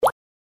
UI_Bubble.mp3